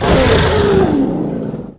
c_croccata_hit1.wav